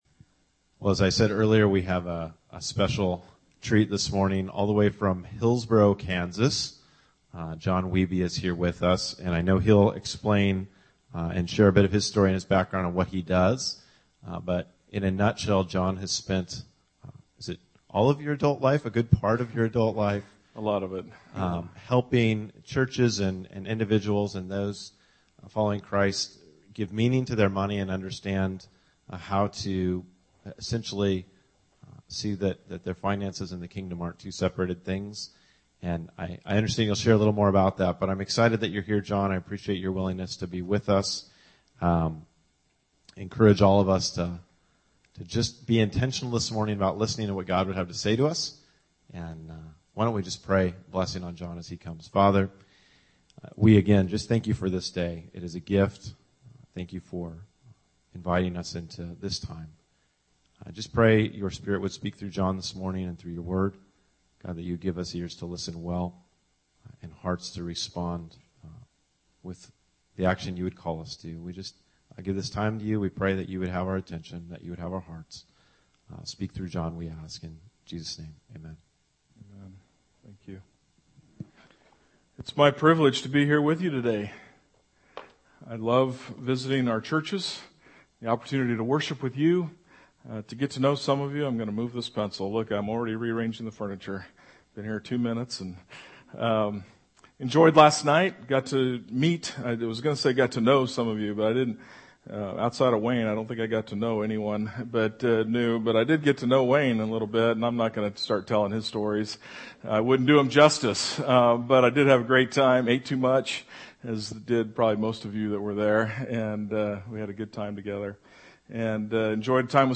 Guest Sermons